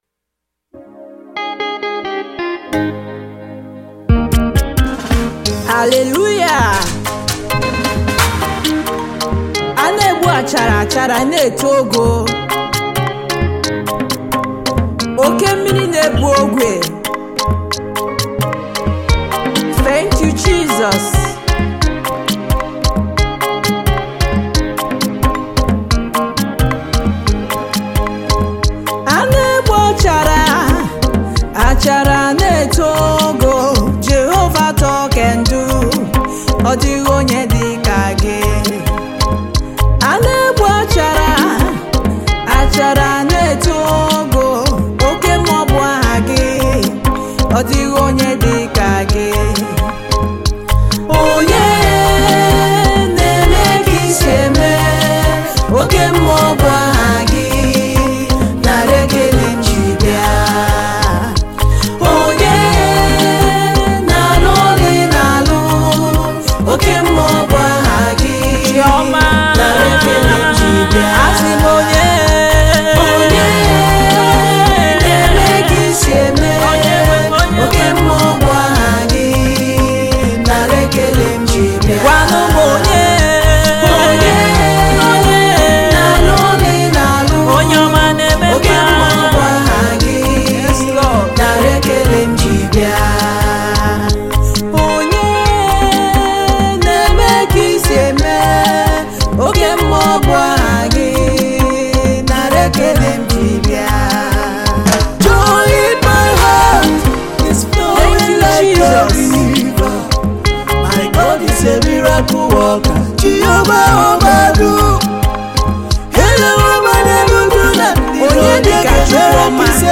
a powerful and uplifting worship anthem available now …